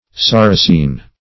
Search Result for " sarrasine" : The Collaborative International Dictionary of English v.0.48: Sarrasin \Sar"ra*sin\, Sarrasine \Sar"ra*sine\, n. [F. sarrasine, LL. saracina.